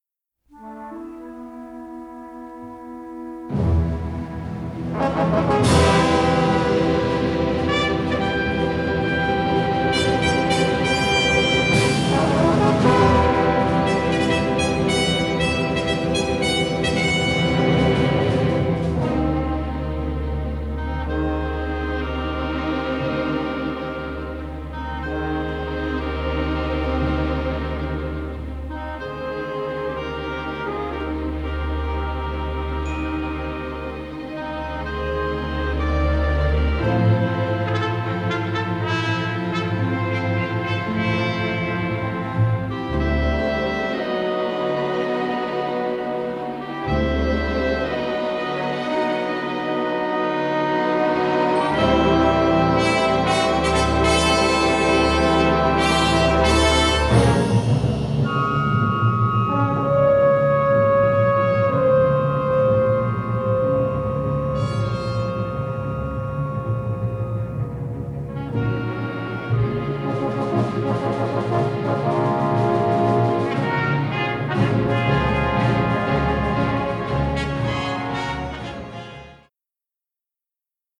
Score recorded at Shepperton Studios in England